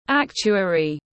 Actuary /ˈæk.tʃu.ə.ri/